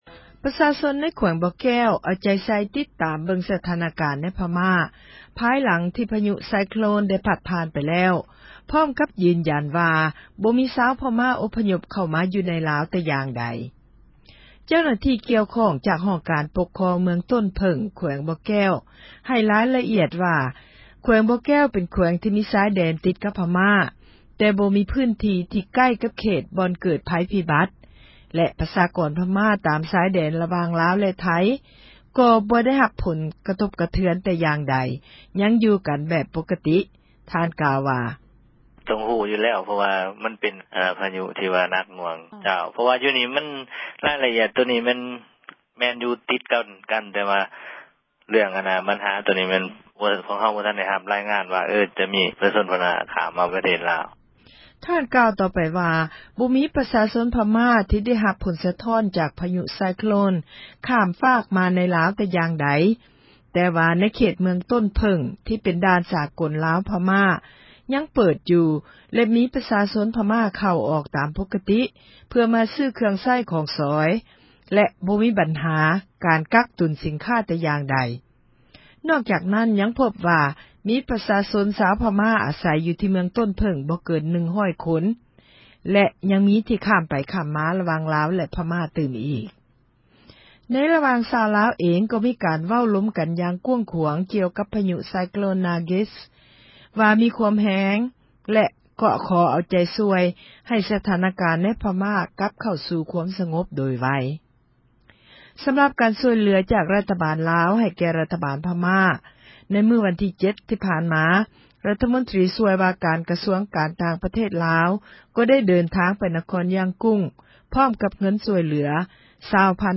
ສຽງນາຣ໌ກິດສ໌ຕົ້ນເຜິ້ງ